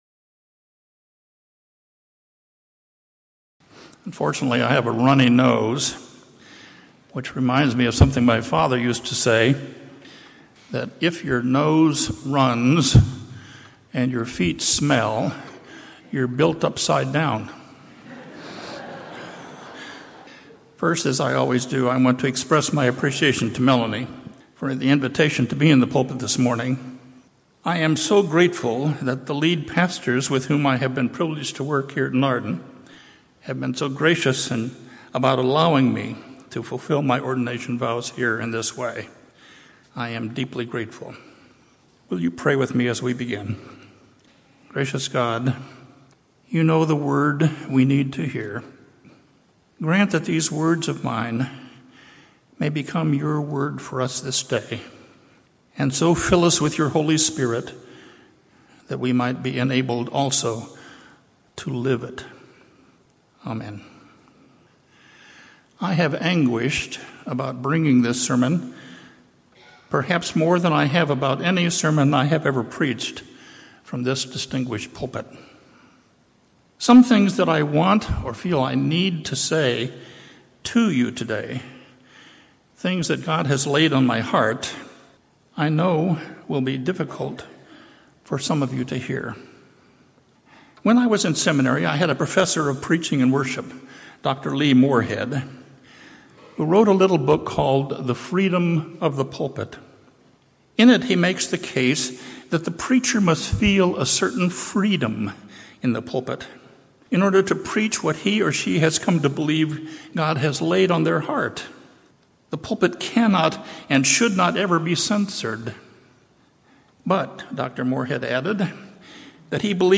July0818-Sermon.mp3